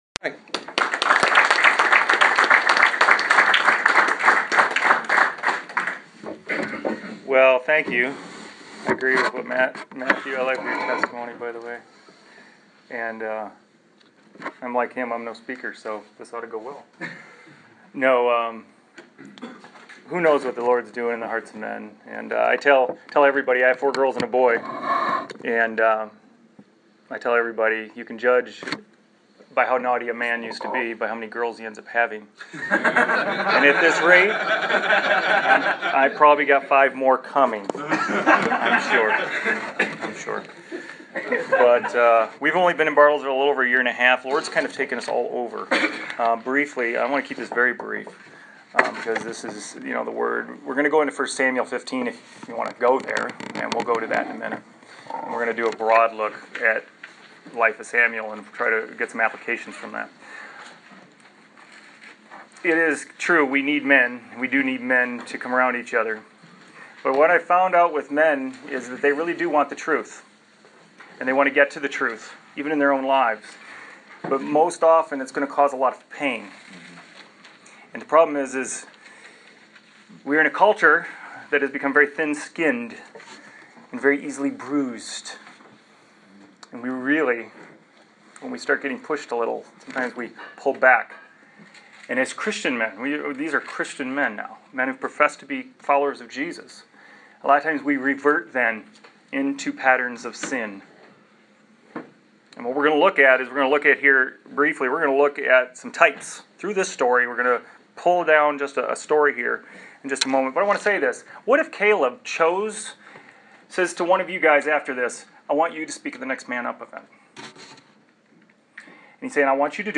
On Saturday, January 31st it was a great privilege to be able to meet up with a group of men at the Man Up breakfast at Trinity Baptist Church in Bartlesville, OK.